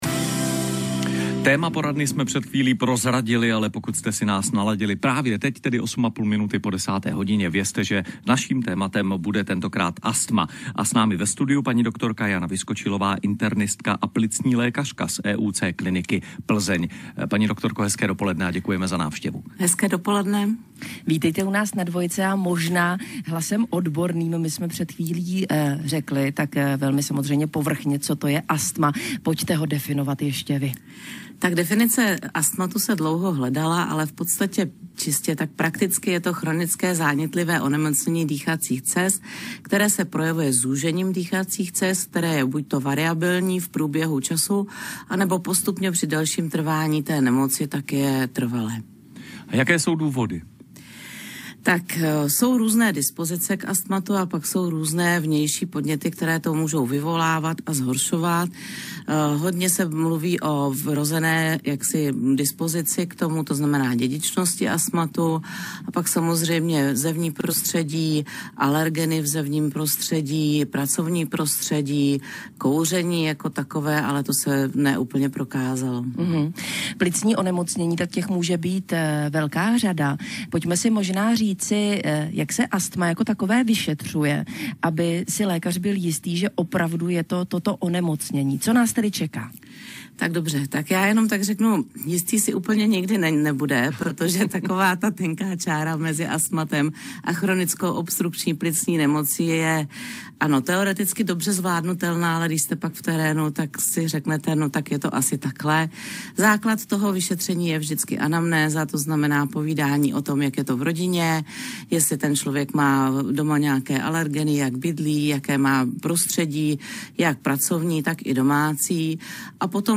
Dva Na Dvojce Rozhovor Na Téma Astma
dva-na-dvojce_rozhovor-na-téma-astma.mp3